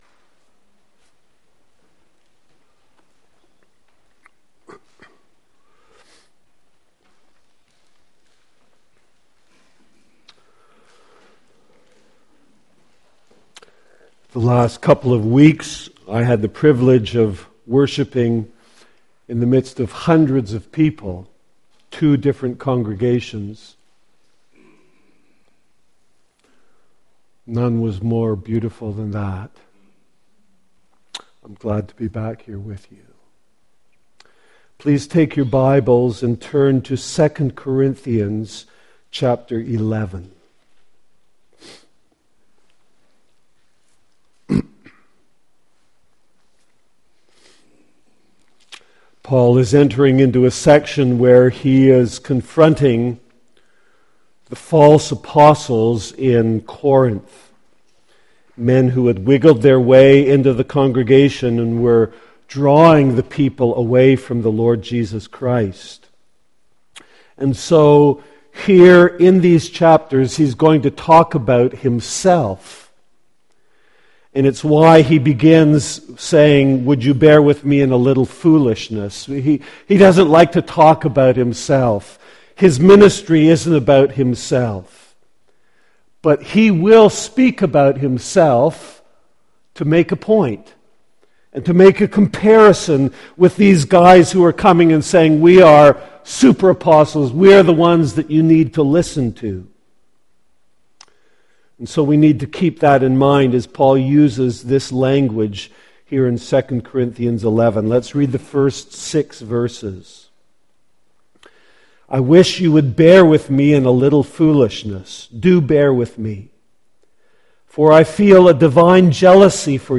Passage: 2 Corinthians 11:1-6 Service Type: Morning Worship